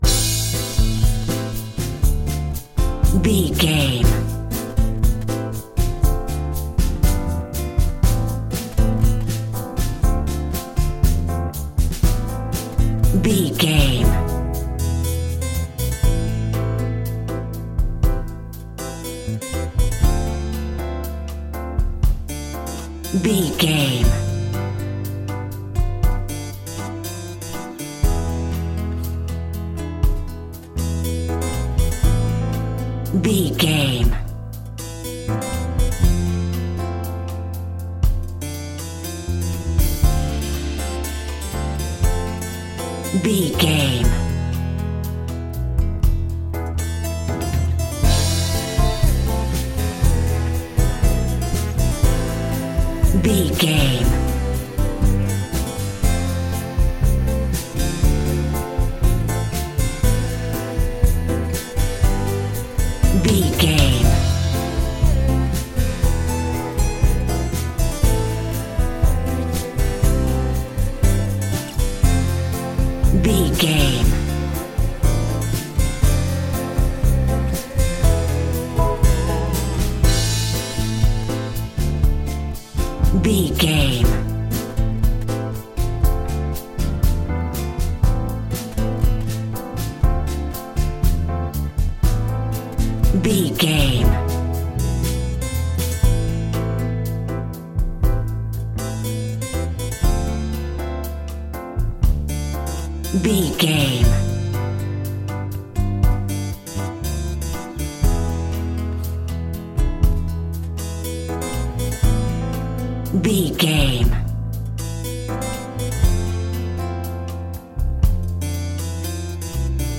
Aeolian/Minor
maracas
percussion spanish guitar
latin guitar